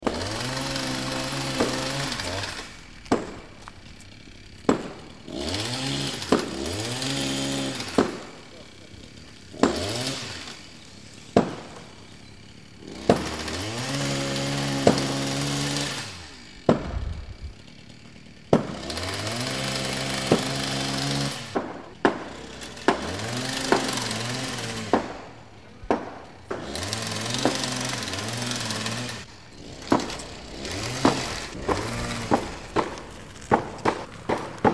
دانلود آهنگ تصادف 15 از افکت صوتی حمل و نقل
دانلود صدای تصادف 15 از ساعد نیوز با لینک مستقیم و کیفیت بالا